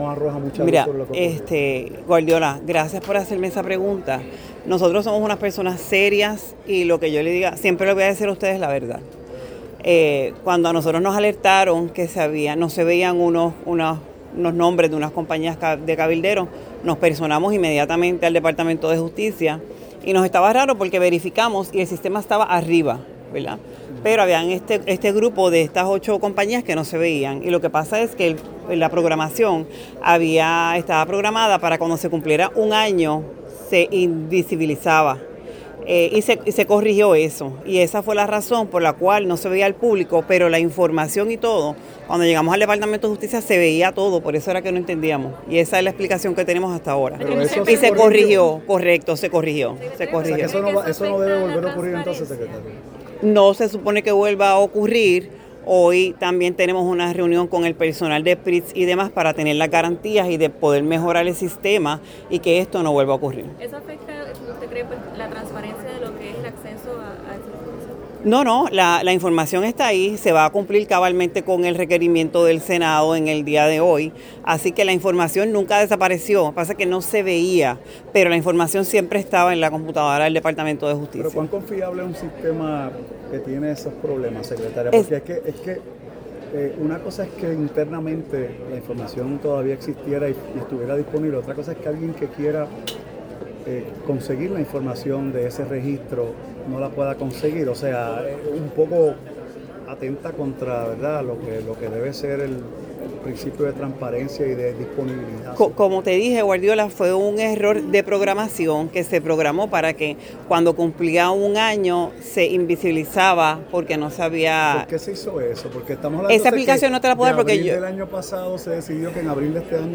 Sobre el particular, la secretaria de Justicia, Lourdes L. Gómez aseguró que ellos son personas serias.